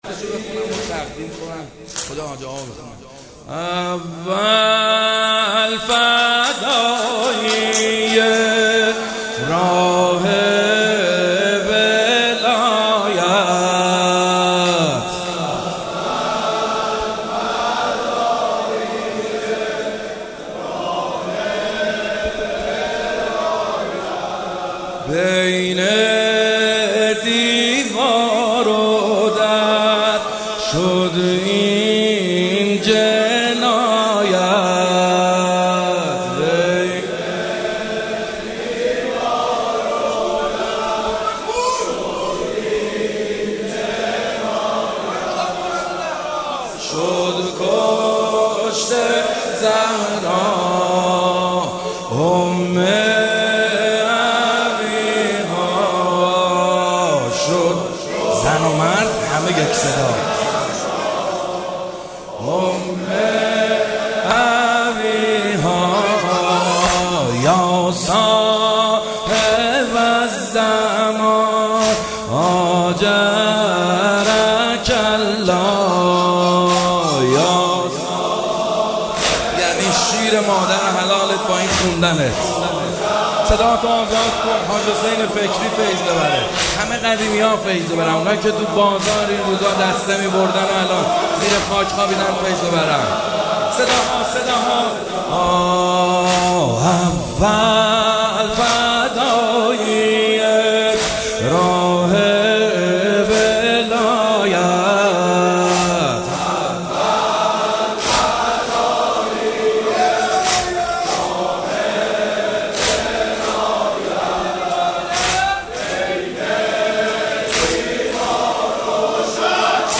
شب اول فاطمیه دوم صوتی - واحد - اول فدایی راه ولایت - محد حسین حدادیان
شب اول فاطمیه دوم صوتی